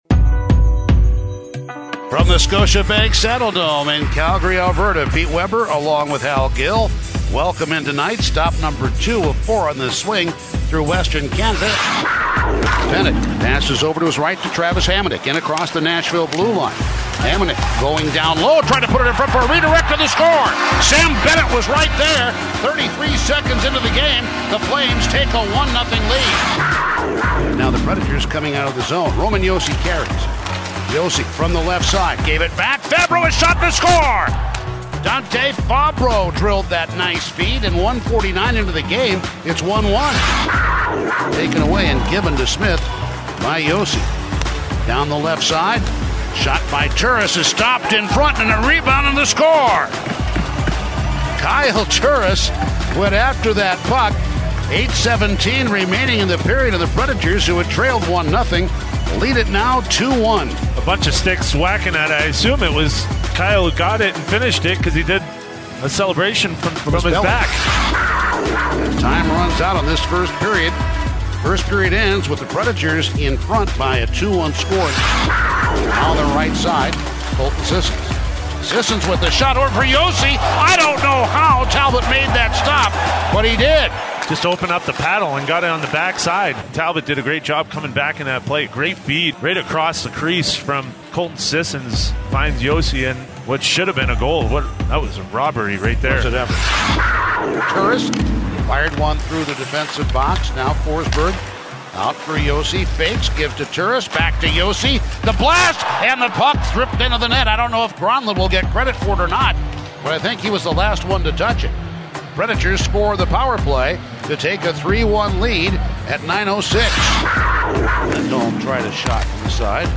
Radio highlights from the Nashville Predators' 3-2 win in Calgary on February 6, 2020, as heard on the 102.5 The Game